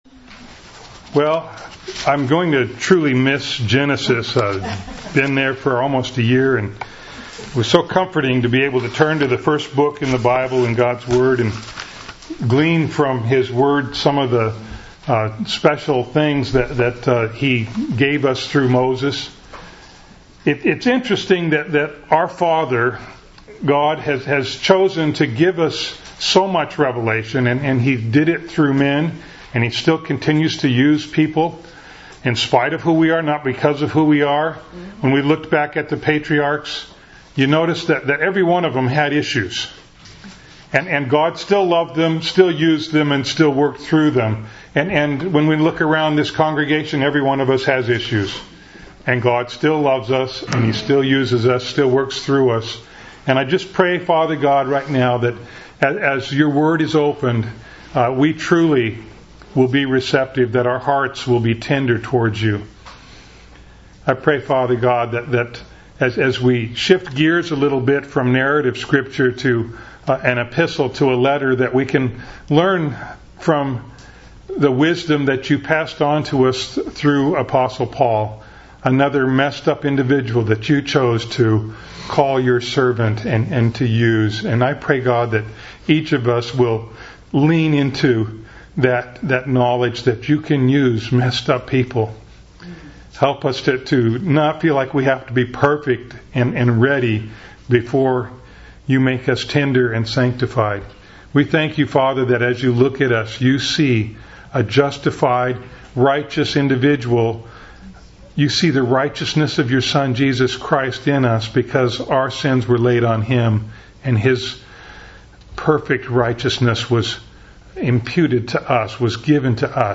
Bible Text: Galatians 1:1-5 | Preacher